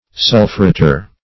Sulphurator \Sul"phu*ra`tor\, n.